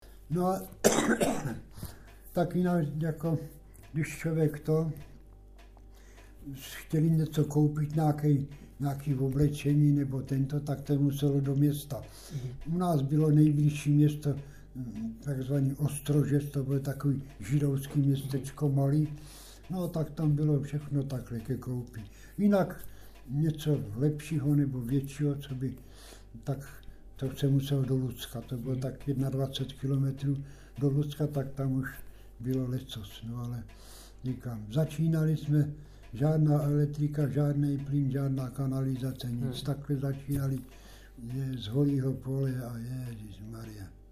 Klip "Hospodaření na Volyni" z vyprávění pamětníka
natočil v roce 2010 v Žatci